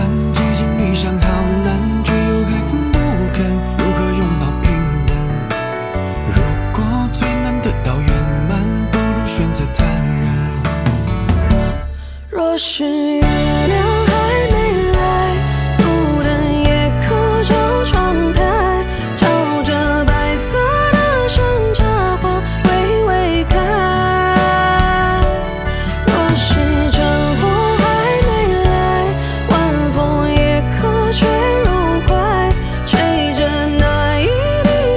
Жанр: Поп
# Mandopop